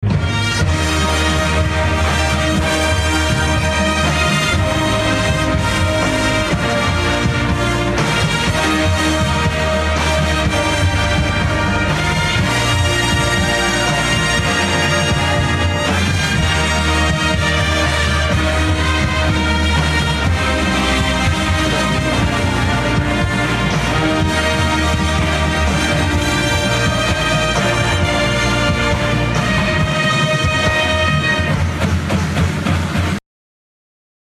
WCU has an indoor marching ensemble, backed by a drumline and rhythm section.